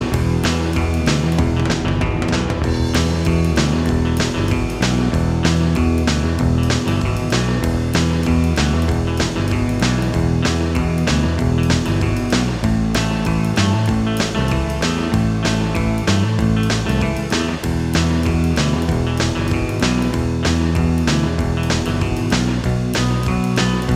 Minus Lead Guitar Rock 3:57 Buy £1.50